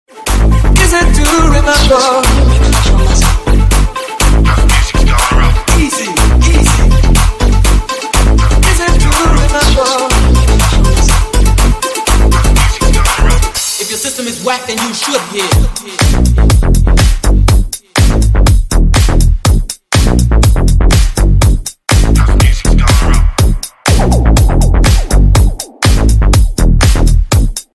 deep house
retromix
nu disco
synthwave